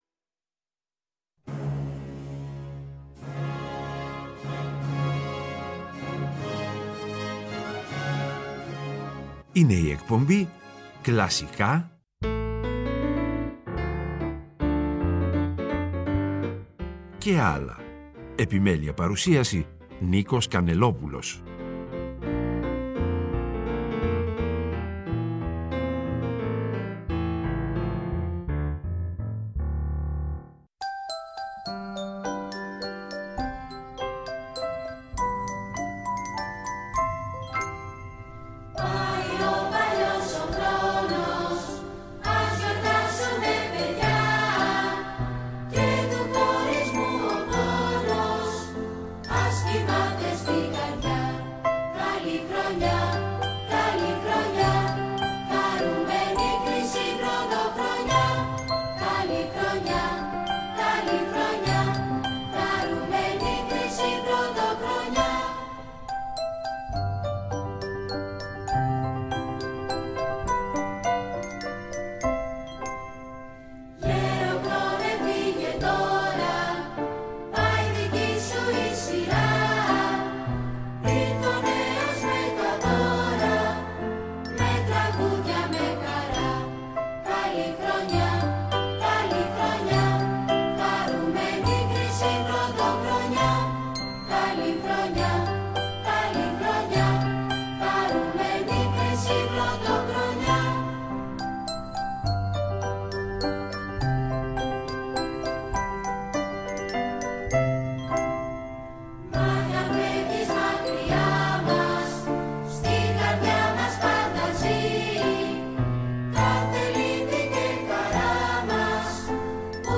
Και, προς το τέλος κάθε εκπομπής, θα ακούγονται τα… «άλλα» μουσικά είδη, όπω ς μιούζικαλ, μουσική του κινηματογράφου -κατά προτίμηση σε συμφωνική μορφή- διασκευές και συγκριτικά ακούσματα.